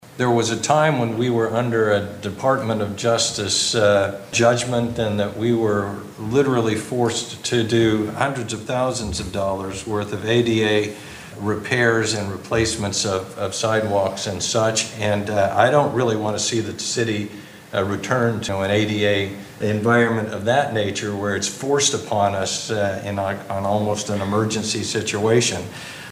Mayor Dean Hayse